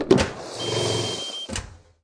Pickup Whirlybird Trigger Sound Effect
pickup-whirlybird-trigger.mp3